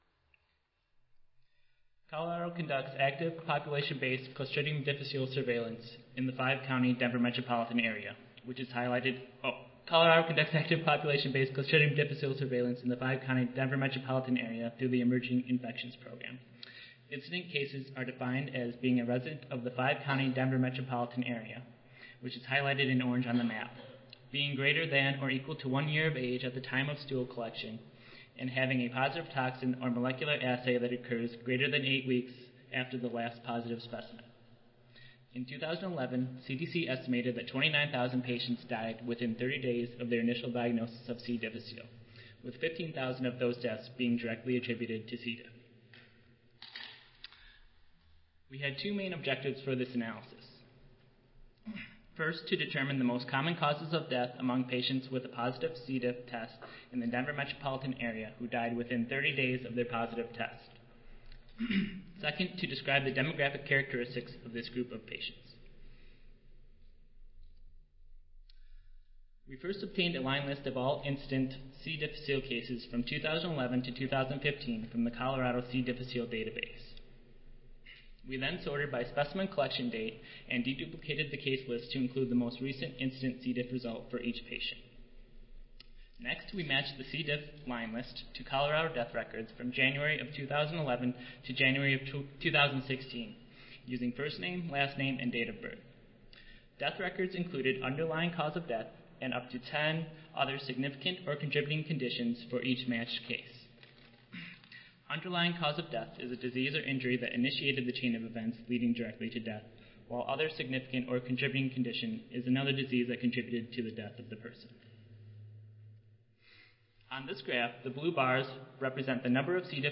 Audio File Recorded Presentation